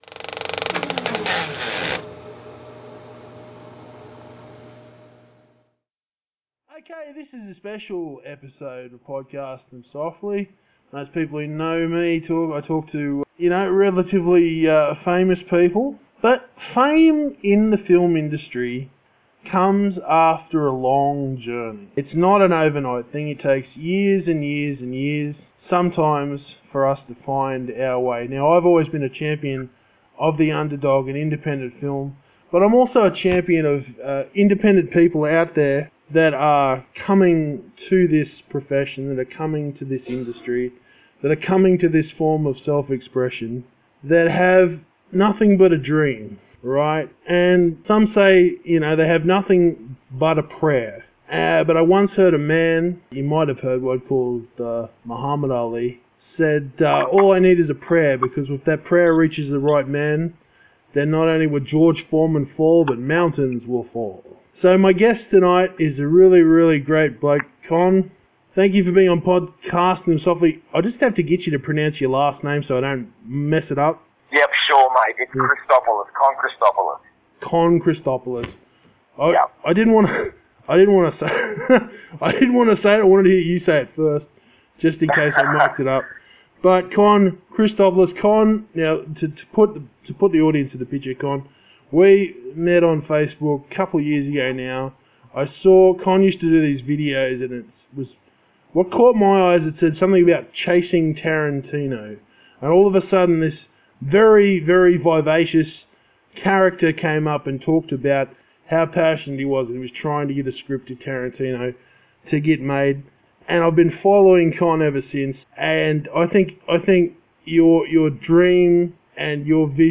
Chasing Tarantino: An Interview